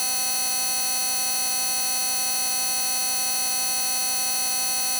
可發現誤差變小了，來聽聽誤差(綠虛線)的聲音。
這聲音就像是工廠機房大機器在作動，且這個聲音也會隨原本要發出的聲音相關。
diff_3bit.wav